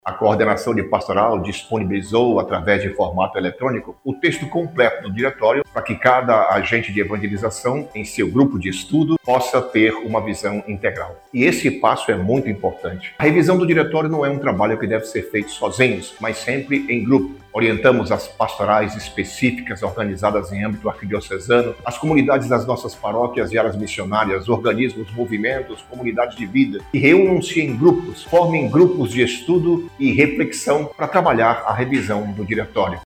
O processo de revisão é realizado em oito etapas, que incluem a leitura integral do texto, reflexões, debates e contribuições de todos os segmentos pastorais e instâncias eclesiais, explica o bispo auxiliar de Manaus, Dom Zenildo Lima.